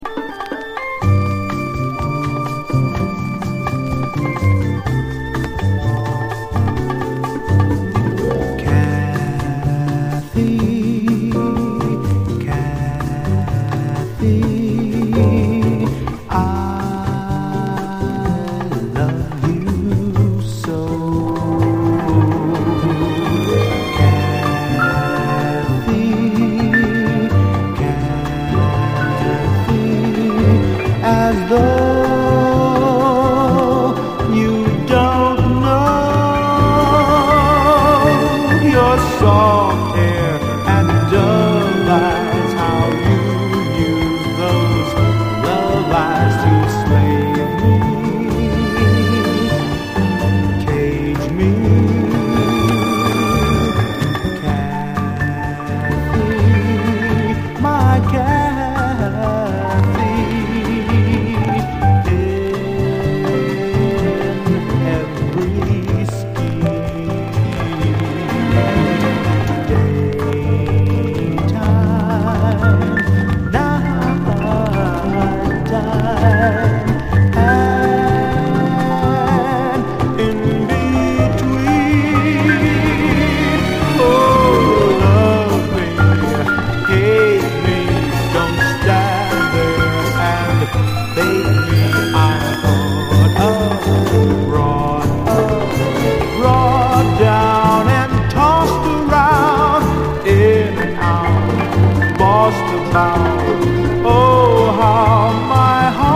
JAZZ, OLDIES, 7INCH
ゾクゾクするようなストリングスと共に儚げなヴォーカルが揺らめきます！
その存在の儚さも相まって、夢のようなサウンドがまさに幻のごとく立ち上ります。